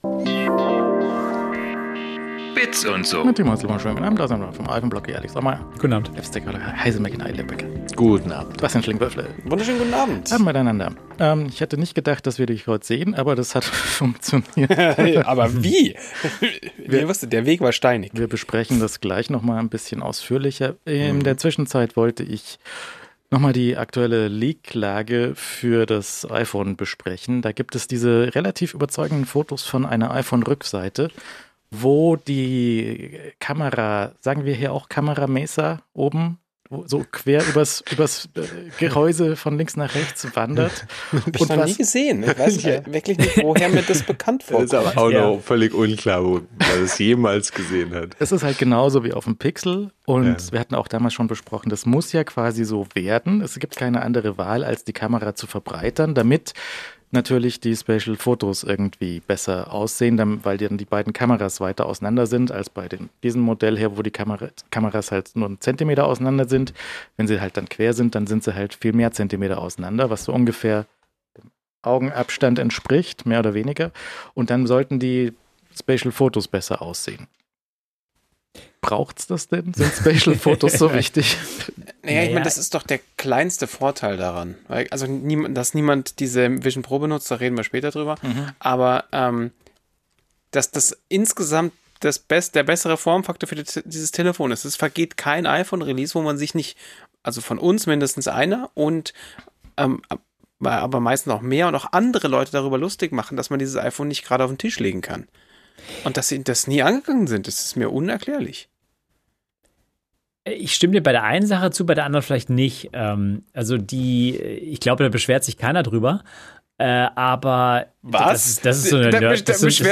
Die wöchentliche Talkrunde rund um Apple, Mac, iPod + iPhone, Gadgets und so. Fast live aus München.